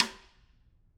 Snare2-taps_v3_rr2_Sum.wav